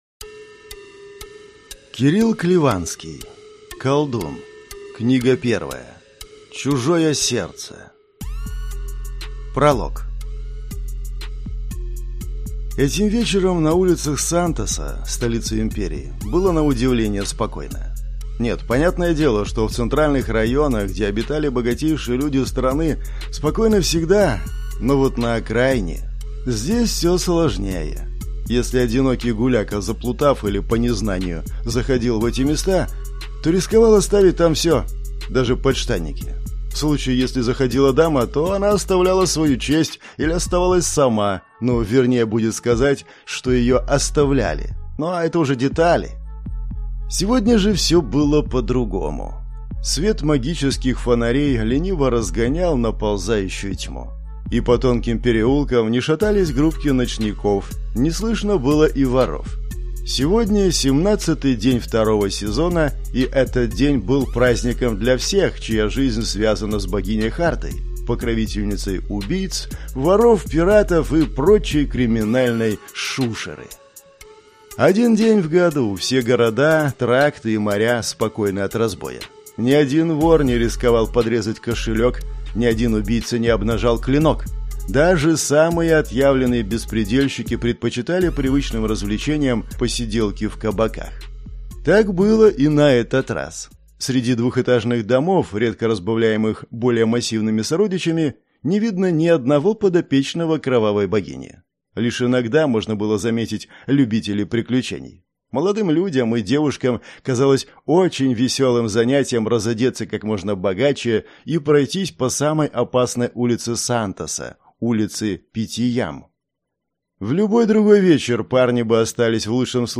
Аудиокнига Колдун. Чужое сердце | Библиотека аудиокниг